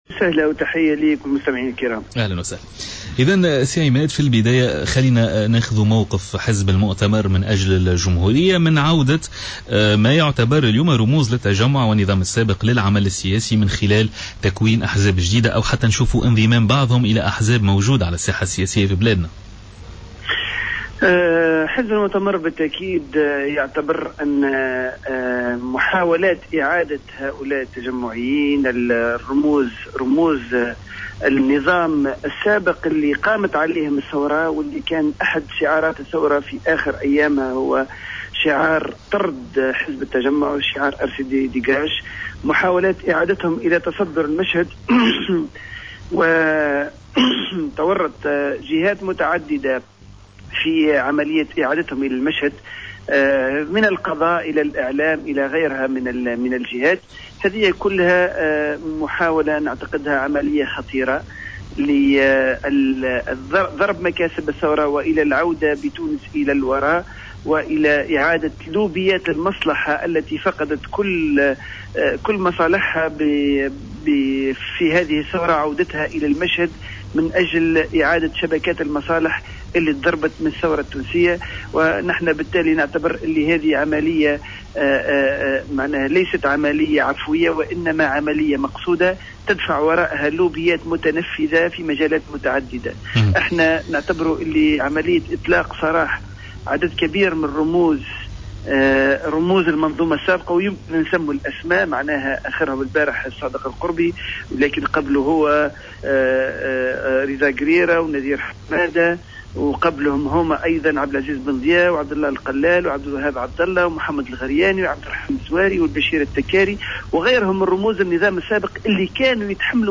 اعتبر الأمين العام للمؤتمر من أجل الجمهورية عماد الدايمي في مداخلة على جوهرة "اف ام" اليوم الخميس 13 مارس 2014 أن محاولات عودة التجمعيين إلى تصدر المشهد السياسي هي محاولات خطيرة لضرب مكاسب الثورة والى العودة بتونس الى الوراء وإعادة لوبيات المصلحة التي تضررت من الثورة مؤكدا تورط عدة جهات وأطراف اعلامية وقضائية في تسهيل هذه العودة على حد قوله.